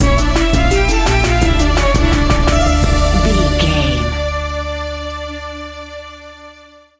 Aeolian/Minor
Slow
World Music
percussion